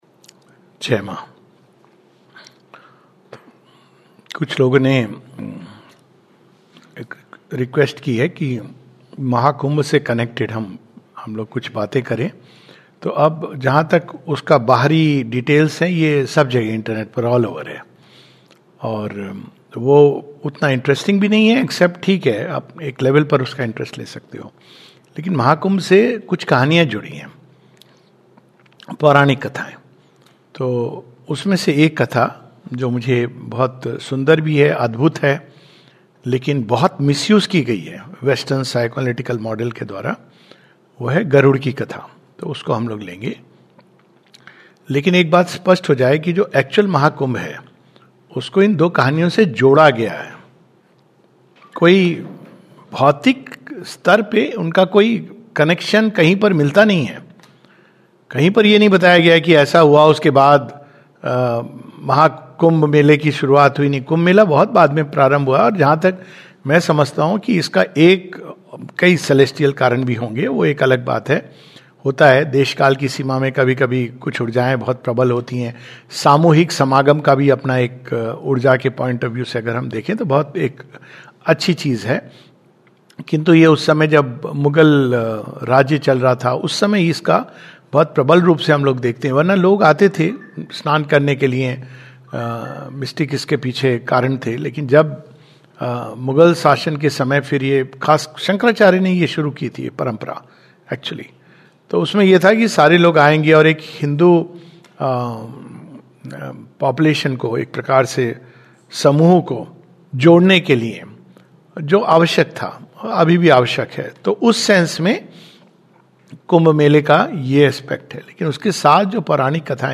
[Story of Garuda, Naga and Amrit] On the occasion of Mahakumbh we take up one of the stories connected to the sacred event taking place in Prayagraj, India - the story of Garuda, Naga, and the Immortalising Nectar. A talk